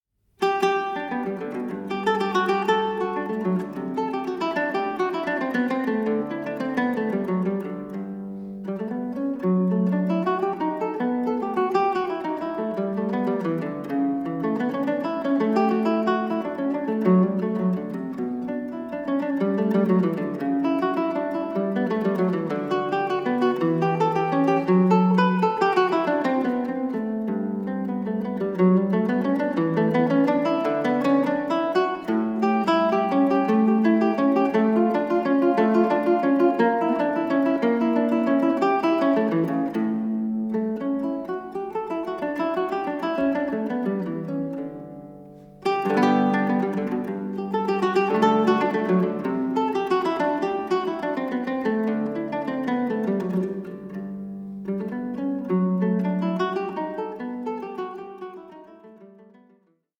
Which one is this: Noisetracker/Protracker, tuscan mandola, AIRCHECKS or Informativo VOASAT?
tuscan mandola